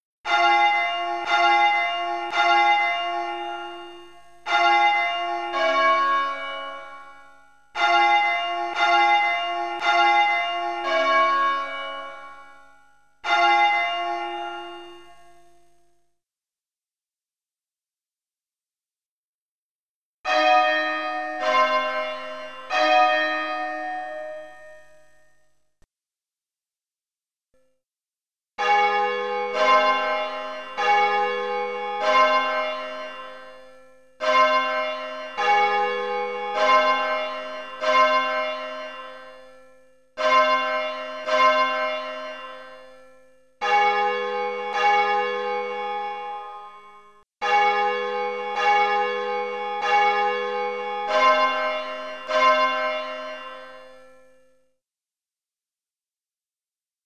dash dot dash dot, dot dash dot dot, dot dot, dash dash, dash dash dash dot dot.
The first code uses a different pair of bells for each word.
There is a small space between each letter, and a longer space between the words, which start again with a higher pitched bell.